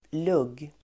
Uttal: [lug:]